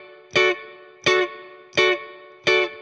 DD_StratChop_85-Dmaj.wav